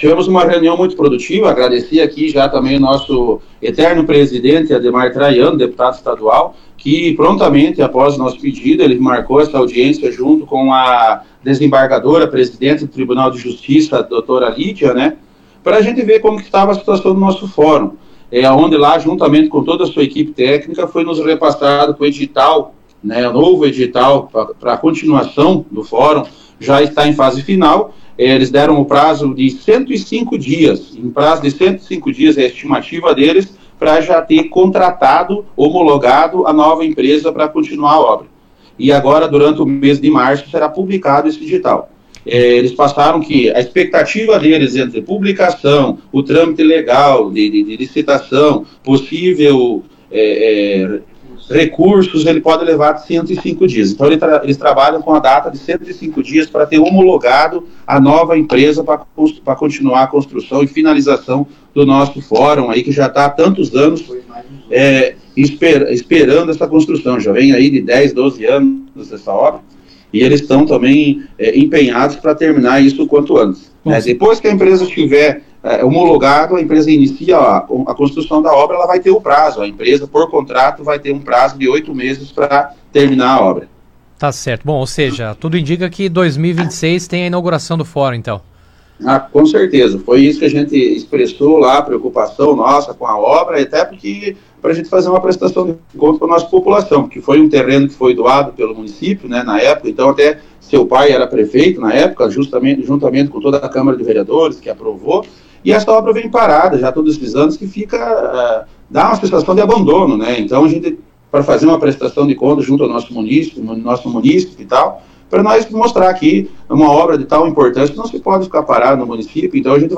O prefeito destacou durante o Jornal RA 2ª Edição desta quarta-feira (26) que o projeto para conclusão será licitado em até 105 dias.